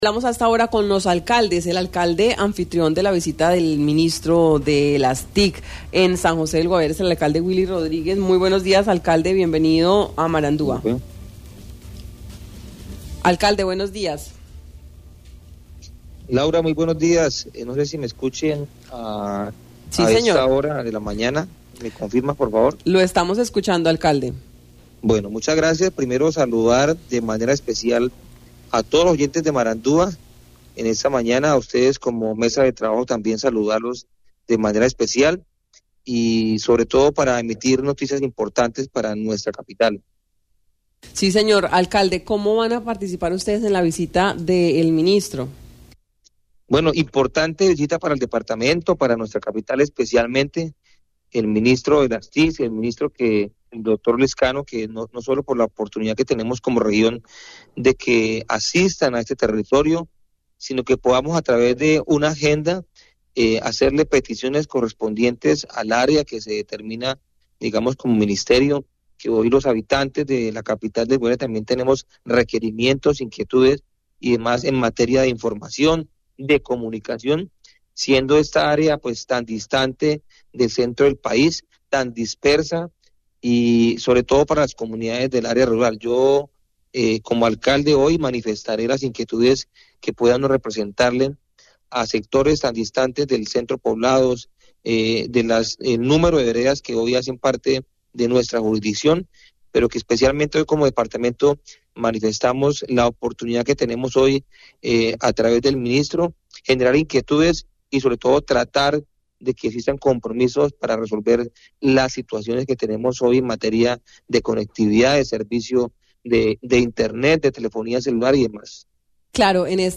Hablamos con el Alcalde Willy Rodríguez, quien como anfitrión de la visita del Ministro de las TIC, Mauricio Lizcano, manifestó que se ha preparado una agenda de solicitudes e inquietudes de la comunidad en materia de conectividad, esperando que al final de la Mesa de Conectividad se establezcan compromisos por parte del ministerio ante las necesidades de la población.